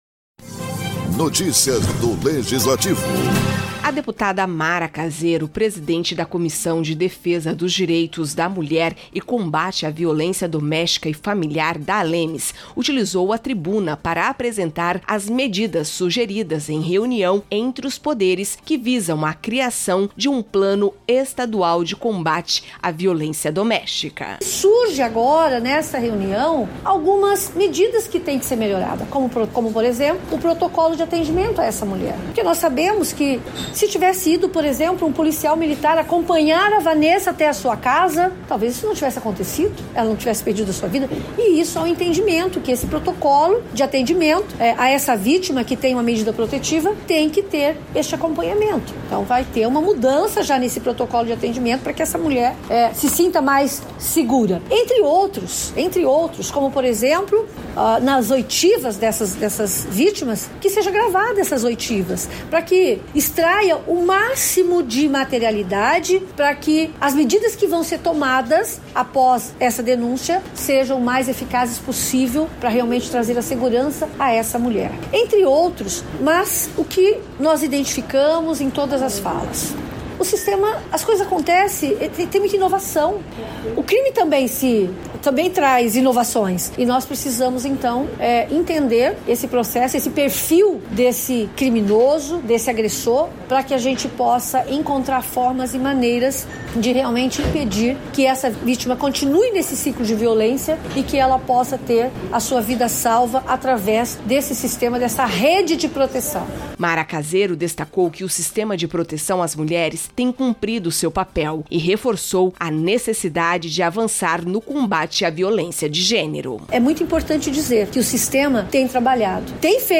A deputada Mara Caseiro destacou na tribuna da ALEMS as medidas sugeridas em reunião entre os Poderes para a criação de um Plano Estadual de Combate à Violência Doméstica em Mato Grosso do Sul.